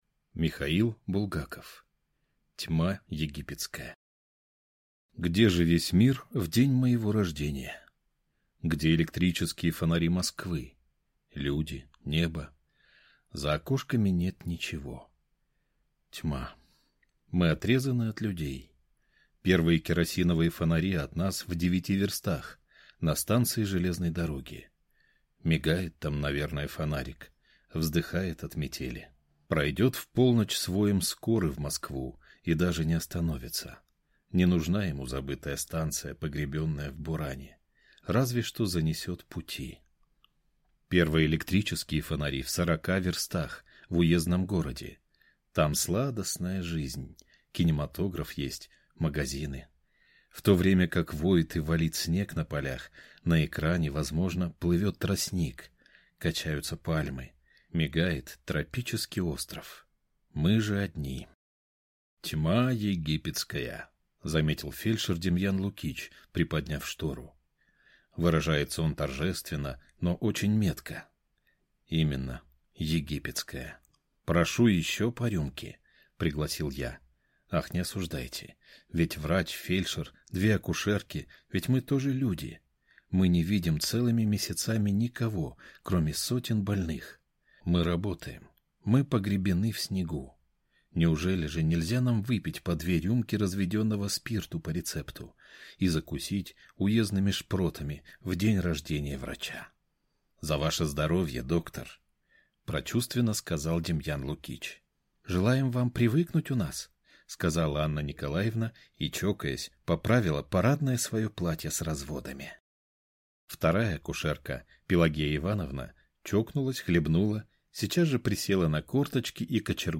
Аудиокнига Тьма египетская | Библиотека аудиокниг
Прослушать и бесплатно скачать фрагмент аудиокниги